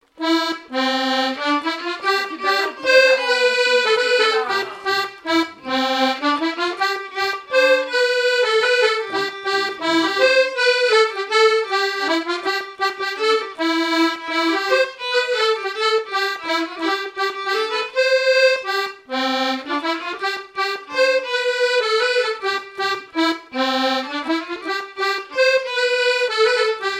danse : mazurka
Pièce musicale inédite